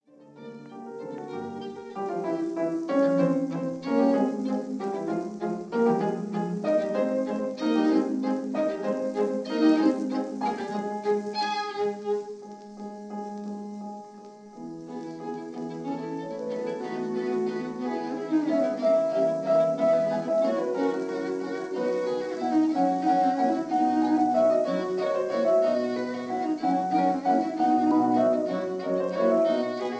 This is the classic 1935 recording
violin
cello